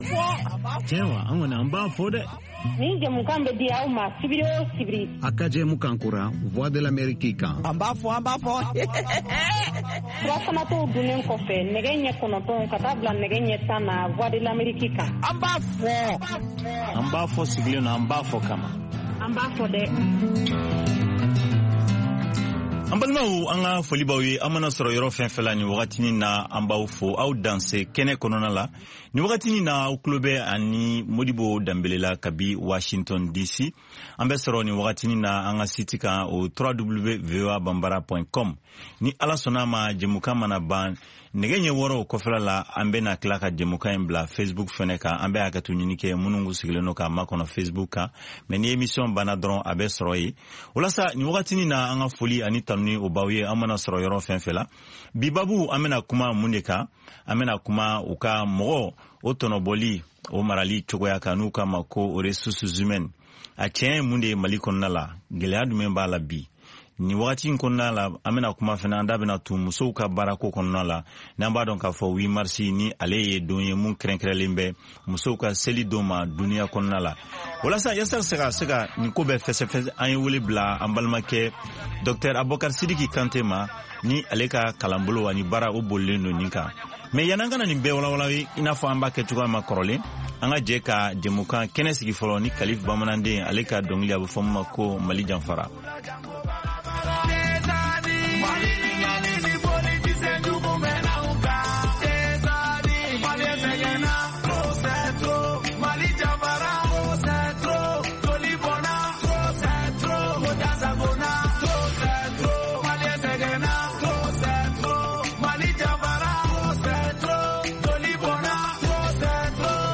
An ba fɔ ye Voix de l’Ameriki ka hakili n’falen n’falen kƐnƐ ye lamƐli kƐlaw ani jamana ɲƐmɔkɔw jamana kunkan kow kan.